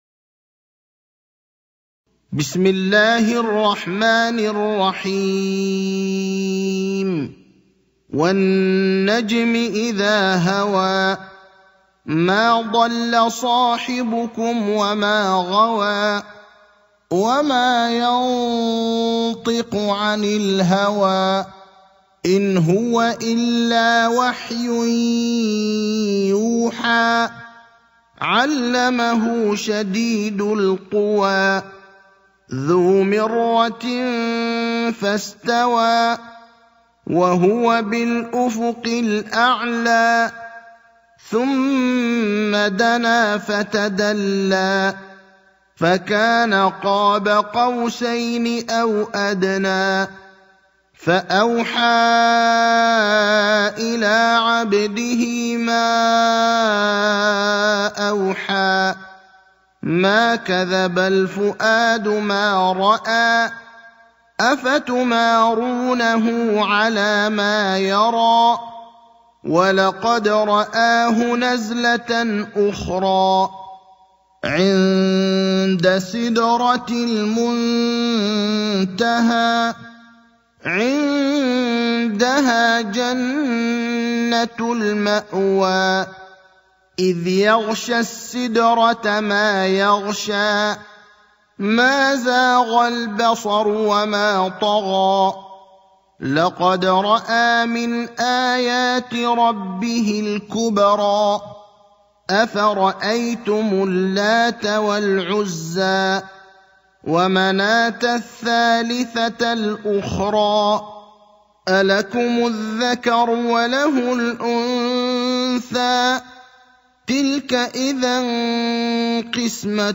برواية حفص